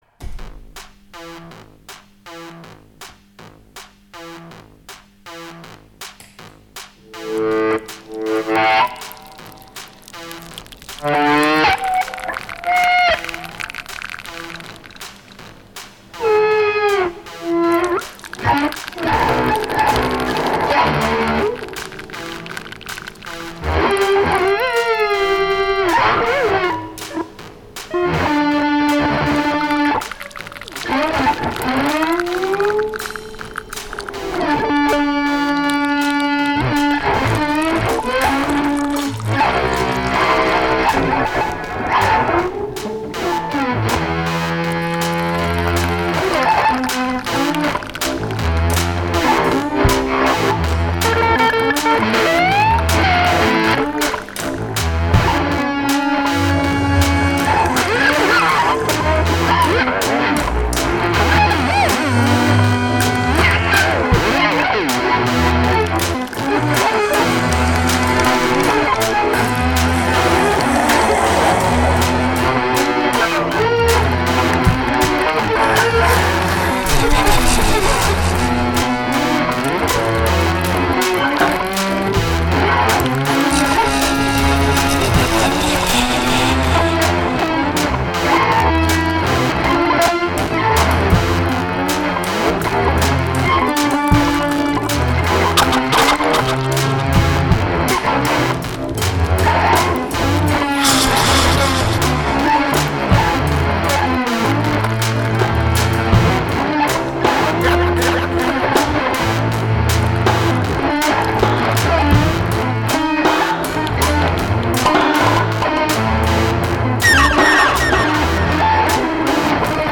enregistré à Herblay